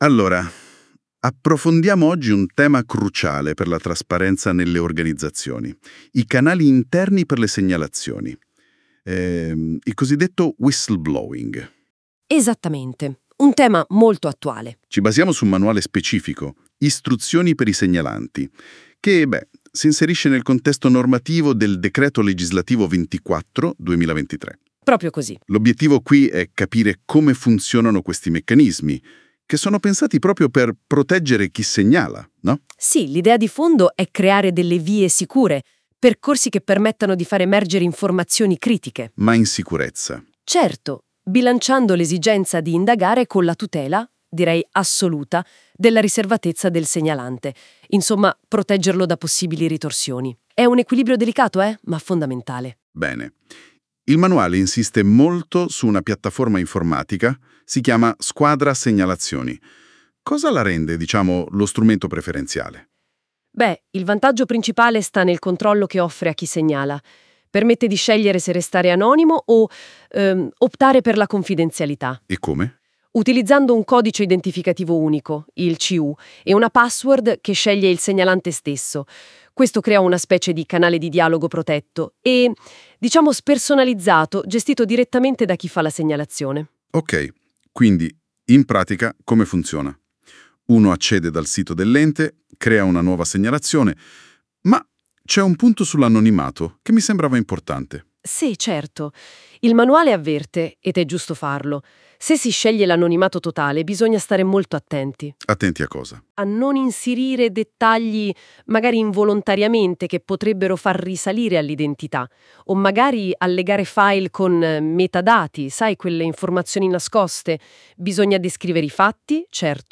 Oltre alle funzionalità già presenti lo scorso anno adesso è possibile ottenere il dibattito audio sulle fonti direttamente in italiano.
NotebookLM_Audio_SQuadra-Segnalazioni.wav